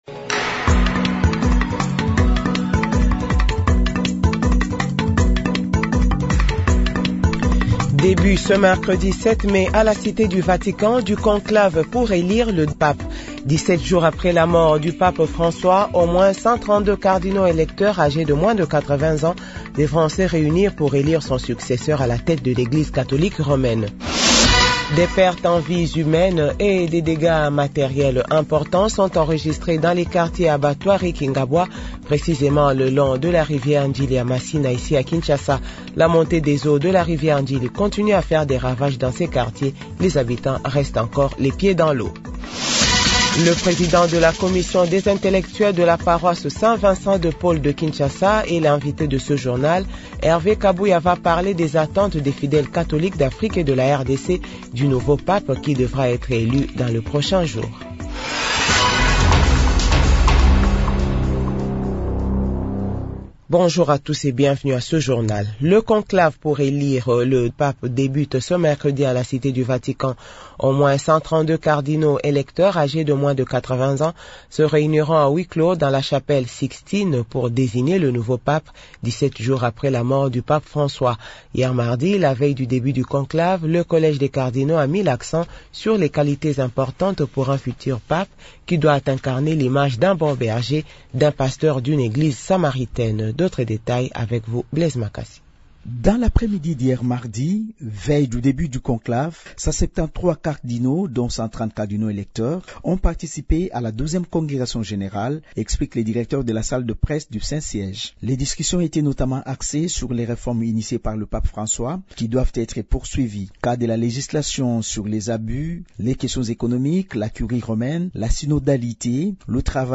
Journal 6h-7h